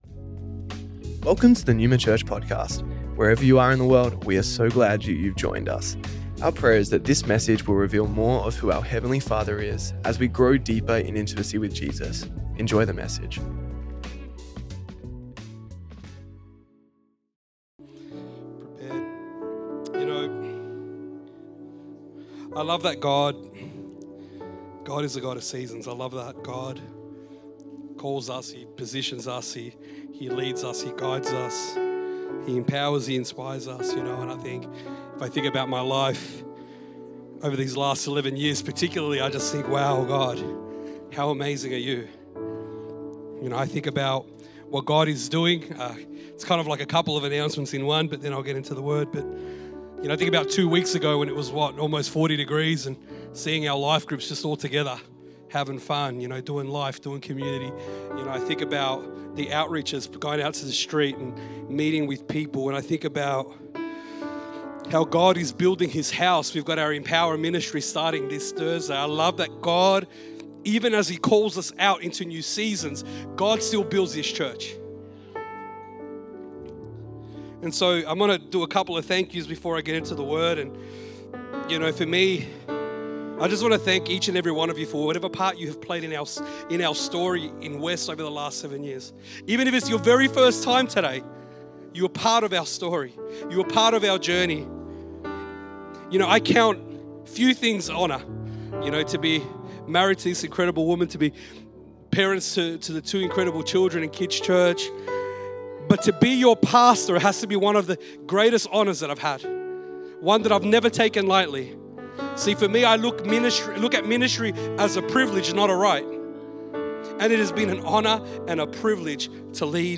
Originally recorded at Neuma Melbourne West on the 2nd March 2025